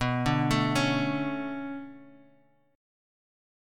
Bm9 Chord
Listen to Bm9 strummed